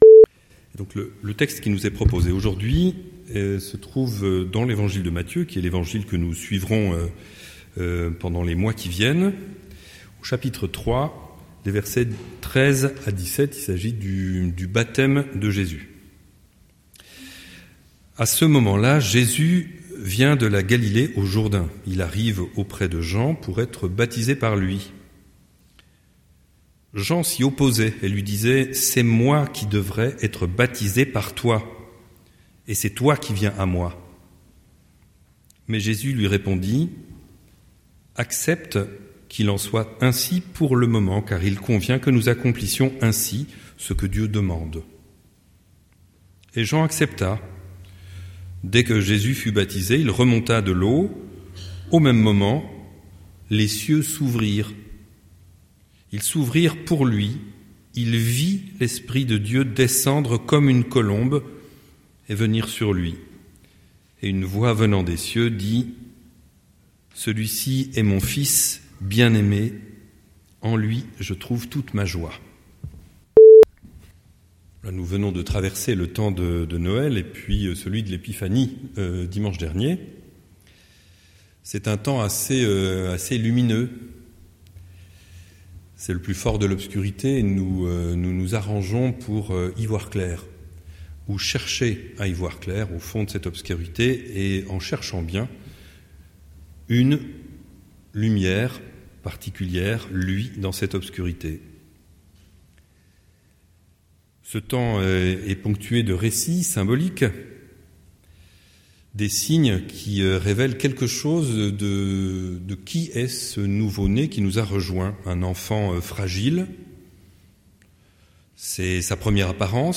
Prédication du 11/01/2026